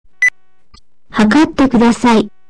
「ピッ」とブザーが鳴り、「測ってください」と音声案内されます。